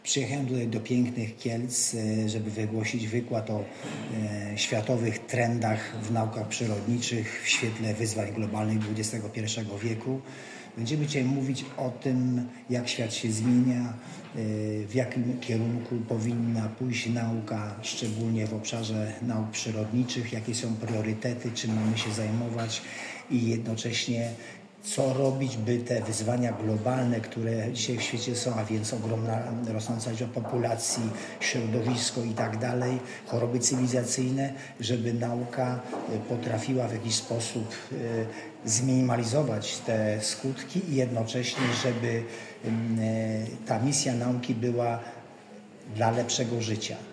Kolejna naukowa środa w Instytucie Biologii UJK w Kielcach
Comiesięczne seminaria naukowe w Instytucie Biologii UJK to już tradycja.